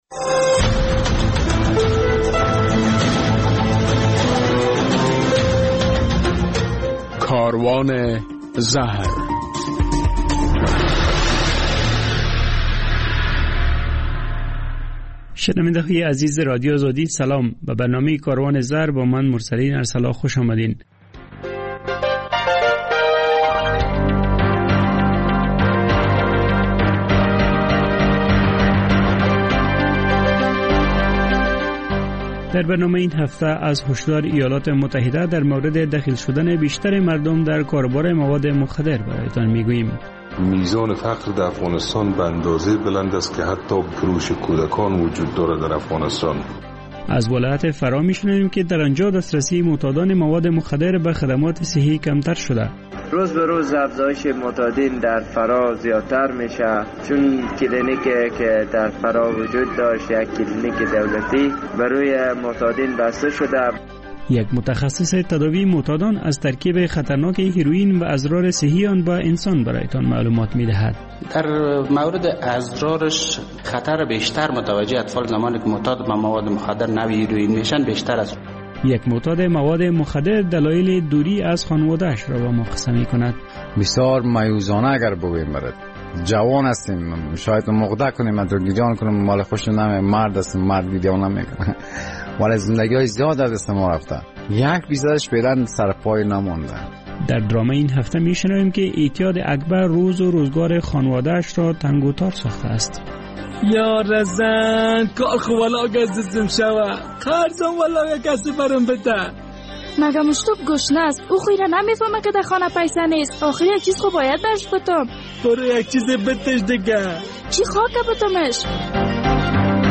در برنامه این هفته کاروان زهر این مطالب جا یافته اند: جزئیات گزارش تازه وزارت خارجه امریکا در رابطه به نگرانی از دخیل شدن بیشتر افغان‌ها در کاروبار مواد مخدر کاهش خدمات صحی به معتادان مواد مخدر مصاحبه با یک داکتر در مورد زیان‌های جدی صحی استفاده از هیرویین به صحت انسان خاطره یک معتاد...